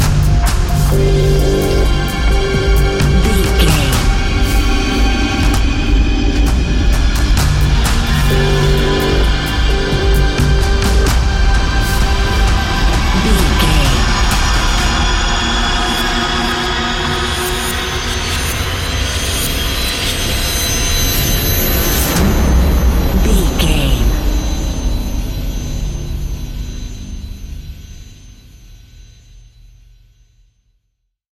Fast paced
Ionian/Major
F♯
industrial
dark ambient
EBM
synths